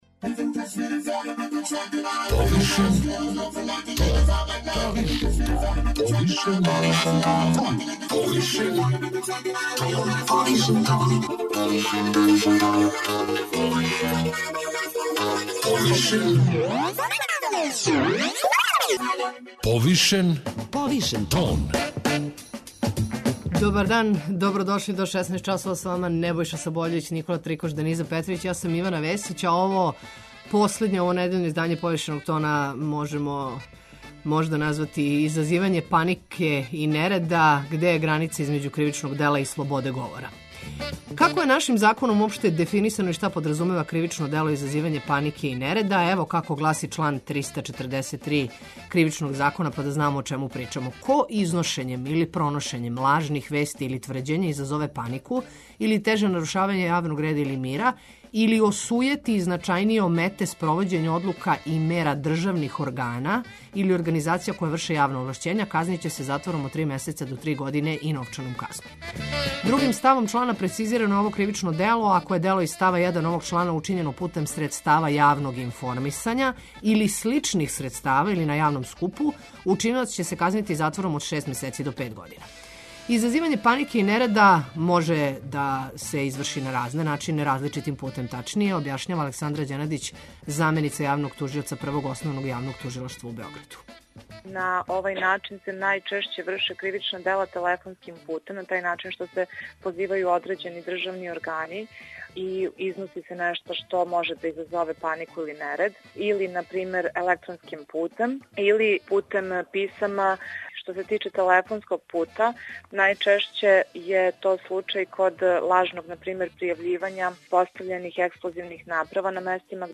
У другом сату емисије позваћемо вас да се придружите традиционалној хуманитарној трци коју 21. априла у оквиру Београдског маратона организује CorD магазин а у рубрици „Датум, време, место" говорићемо о почетку уређивања Ботаничке баште Београда везаном за данашњи датум 1890. године.